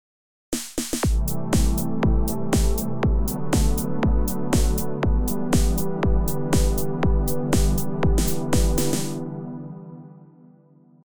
キックをトリガーにするので、キック、ハイハット＆スネア、パッドの3トラックを用意しました。